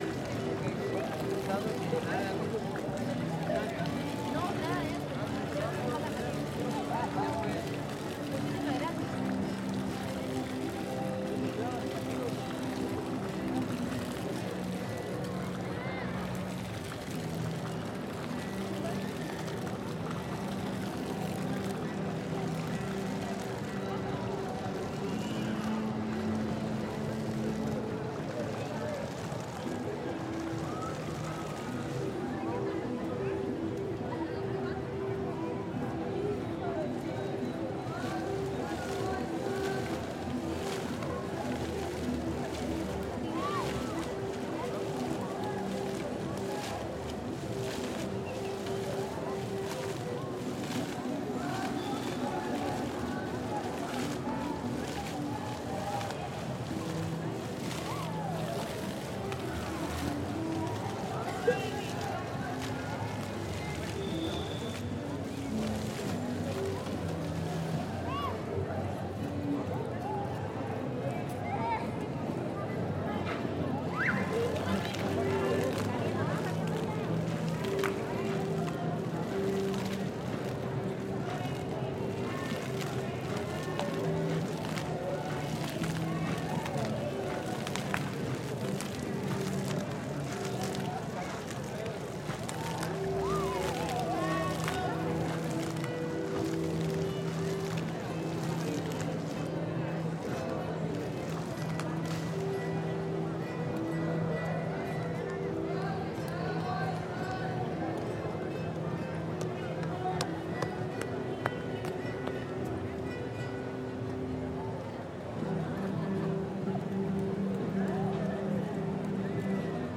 Guitarra y chorros de la Plaza de la Cultura
Guitarra y chorros de la Plaza de la Cultura ACM 2018.mp3